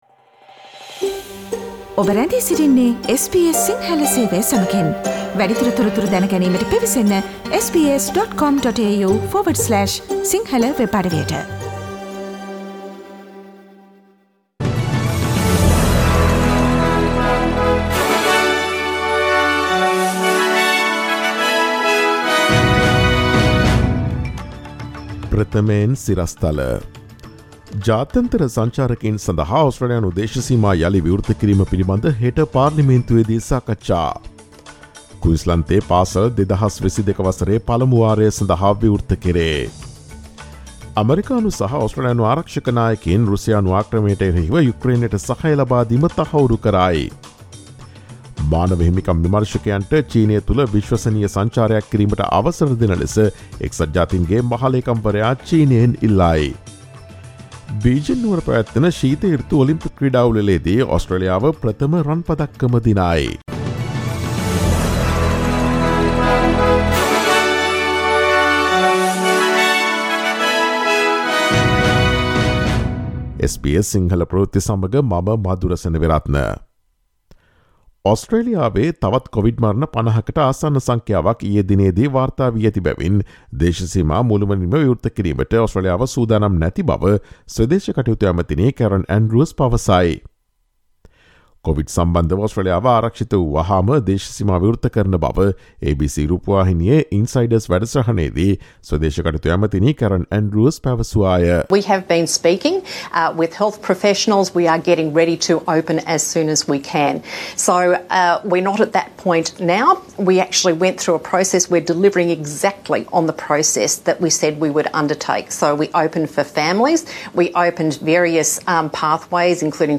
ඔස්ට්‍රේලියාවේ නවතම පුවත් මෙන්ම විදෙස් පුවත් සහ ක්‍රීඩා පුවත් රැගත් SBS සිංහල සේවයේ 2022 පෙබරවාරි 07 වන දා සඳුදා වැඩසටහනේ ප්‍රවෘත්ති ප්‍රකාශයට සවන් දීමට ඉහත ඡායාරූපය මත ඇති speaker සලකුණ මත click කරන්න.